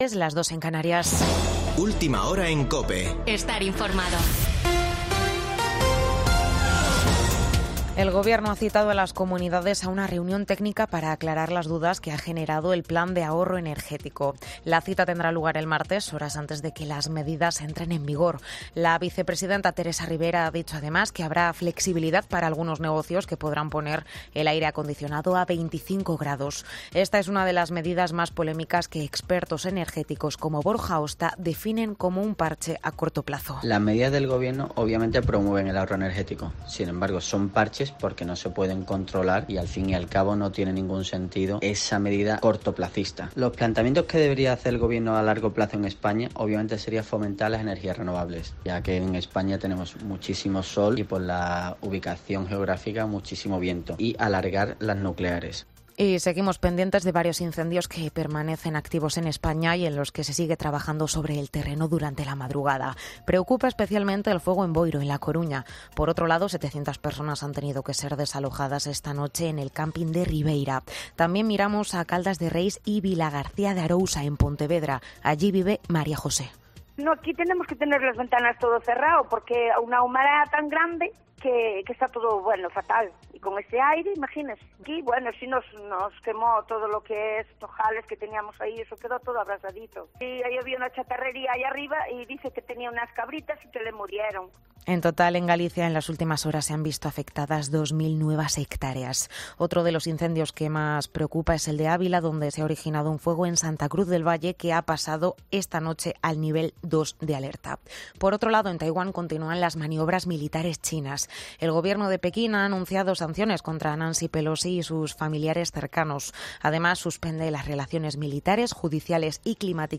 Boletín de noticias de COPE del 6 de agosto de 2022 a las 03.00 horas